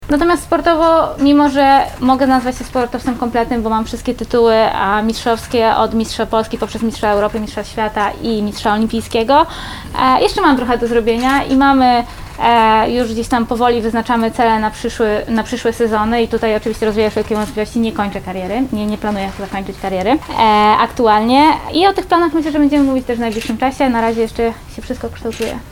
Mistrzyni olimpijska w Lublinie – konferencja prasowa z Aleksandrą Mirosław